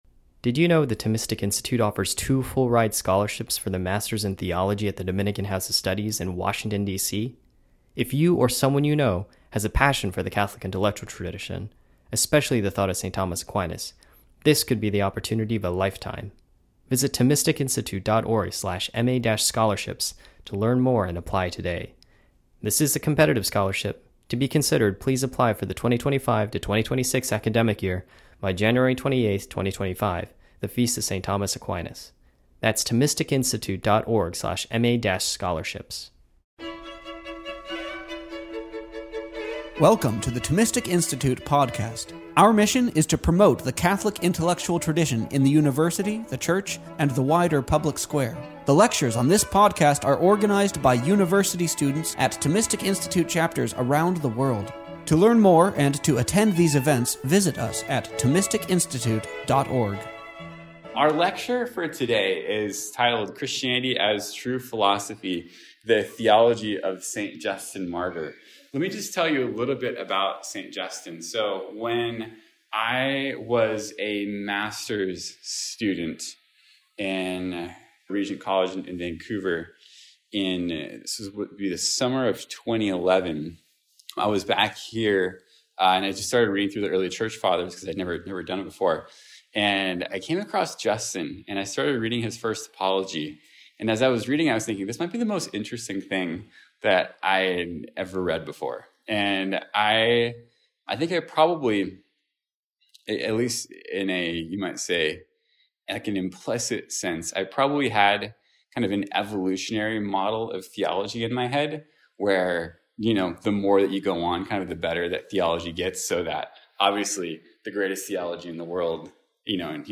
The Thomistic Institute Podcast features the lectures and talks from our conferences, campus chapters events, intellectual retreats, livestream events, and much more.